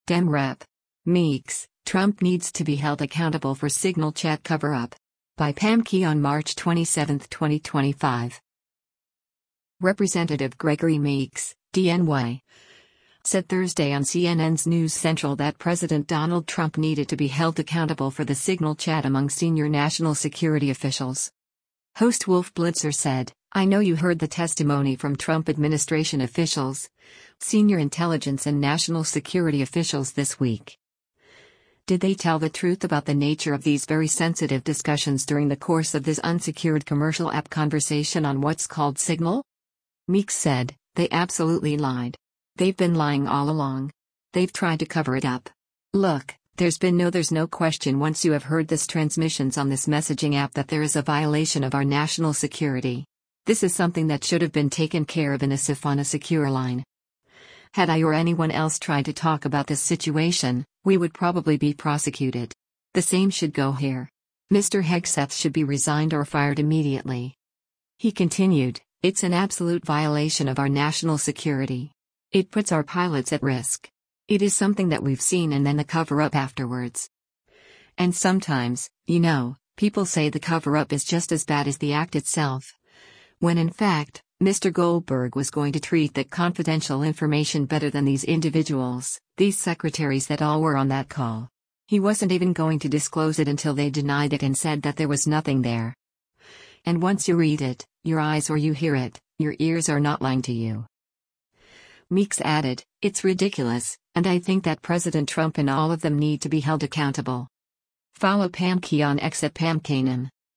Representative Gregory Meeks (D-NY) said Thursday on CNN’s “News Central” that President Donald Trump needed to be “held accountable” for the Signal chat among senior national security officials.